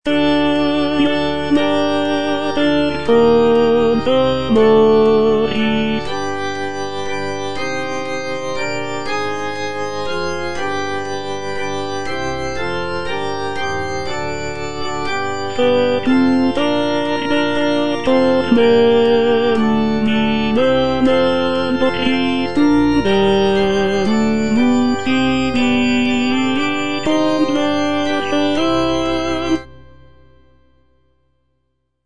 G.P. DA PALESTRINA - STABAT MATER Eja Mater, fons amoris (tenor II) (Voice with metronome) Ads stop: auto-stop